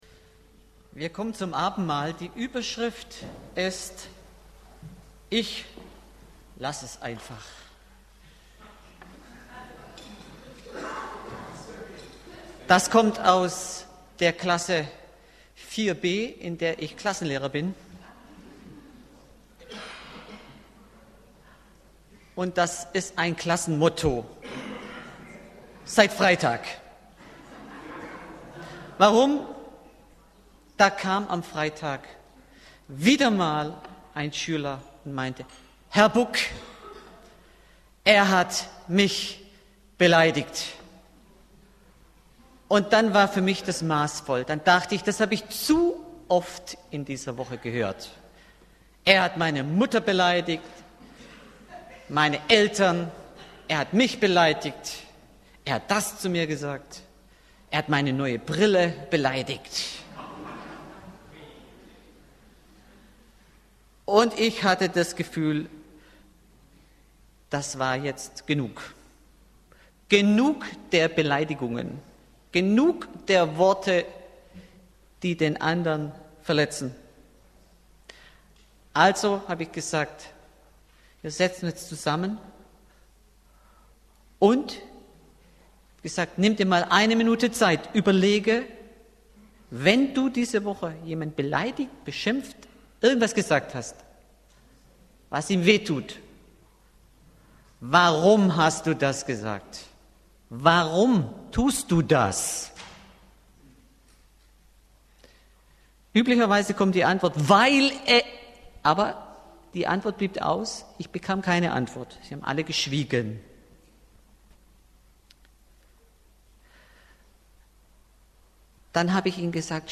Abendmahl Prediger